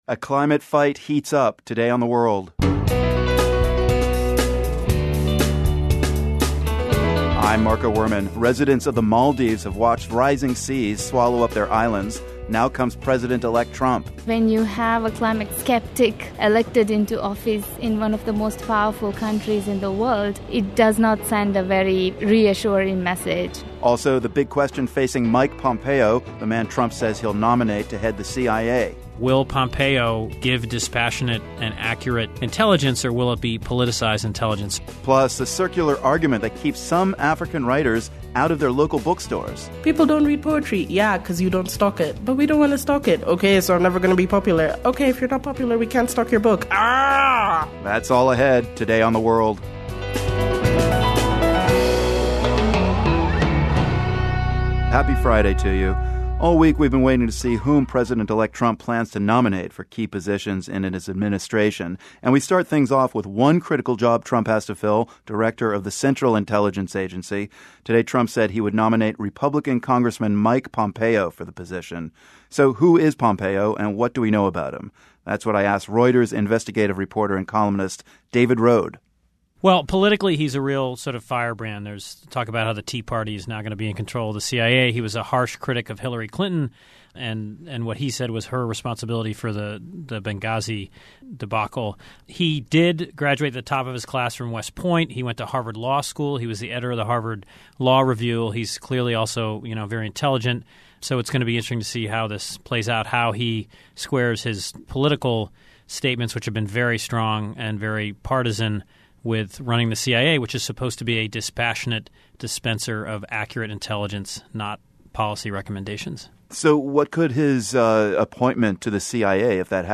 Plus, a climate activist from the Maldives reflects on what it would mean if the US pulls out of the latest global climate deal. And Russian-born comedian Eugene Mirman tells us about finding the funny in America's post-election reality.